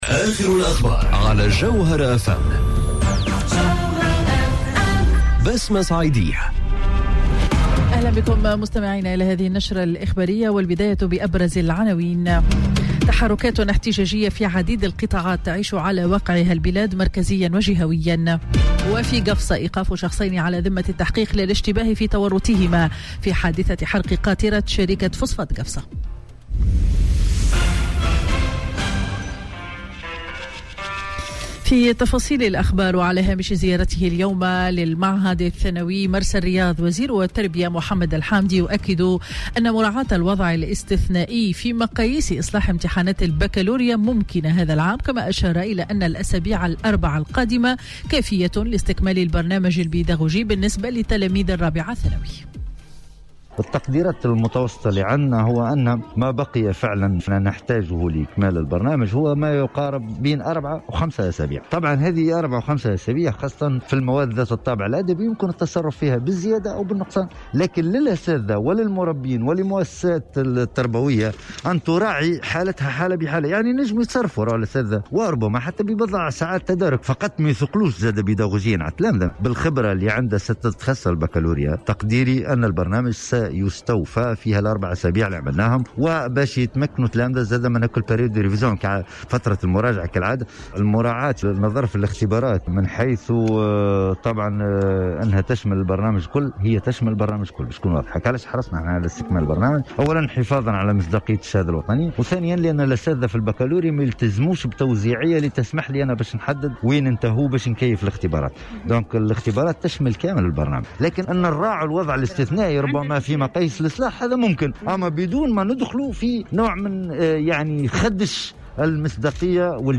نشرة أخبار منتصف النهار ليوم الخميس 28 ماي 2020